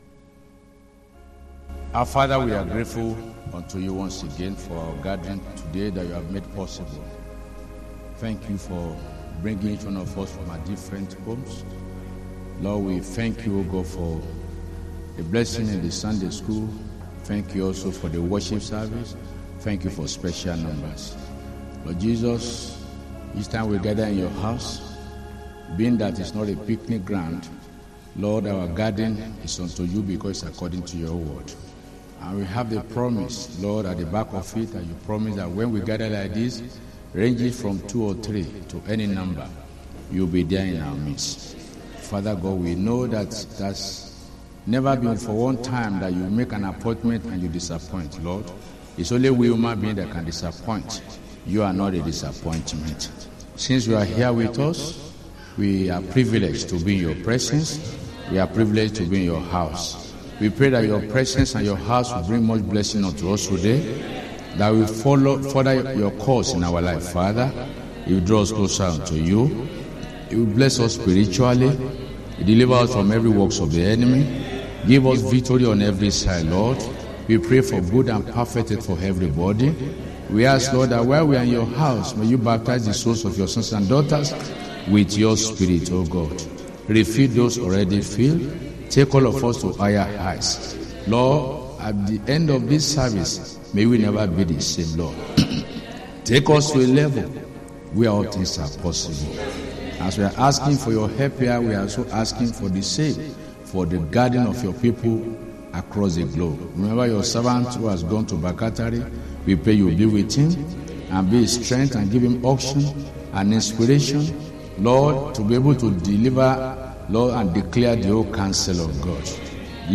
SUN MORN SERVICE